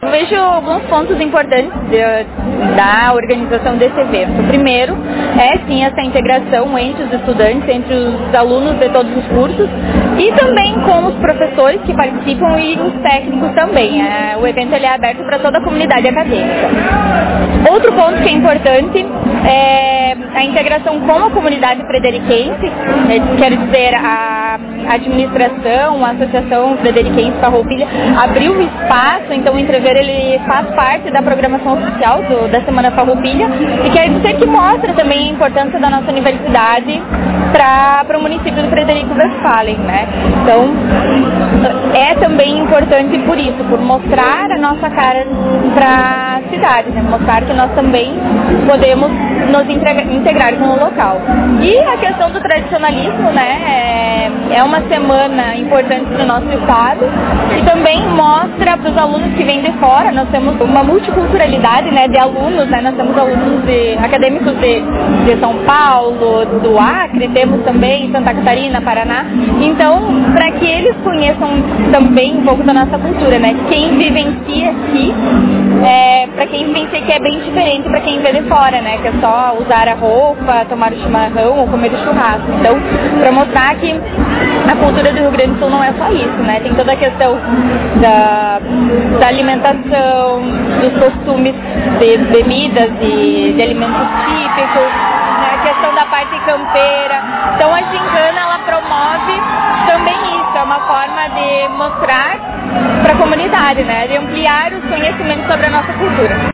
comenta que a Gincana é uma forma de integração entre os próprios alunos e também com a comunidade em geral: